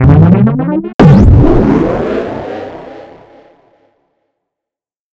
warp.wav